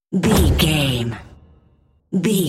Dramatic hit slam door
Sound Effects
heavy
intense
dark
aggressive